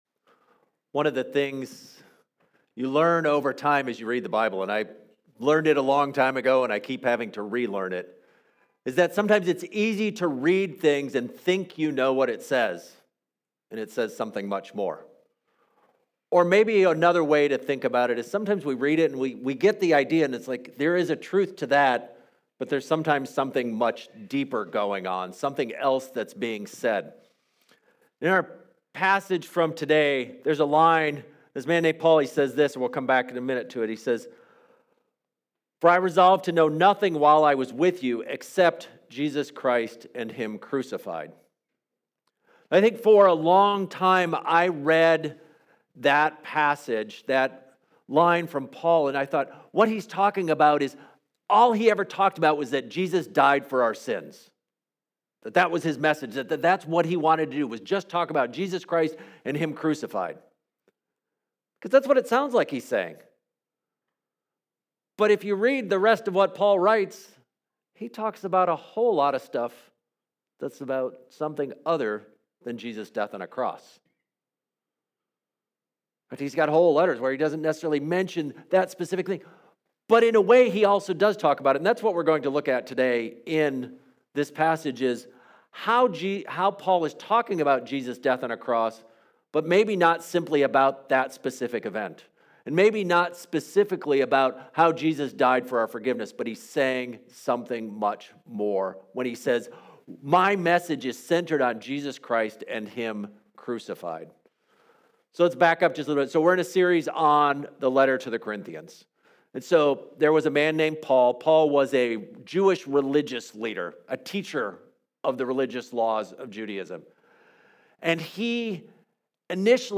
Sermons | Fruitland Covenant Church